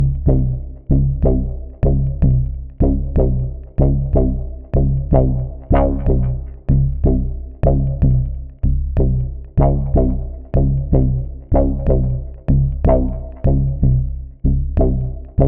• detroit funkin moog bass house 124 - Cm.wav
detroit_funkin_moog_bass_house_124_-_Cm_1sQ.wav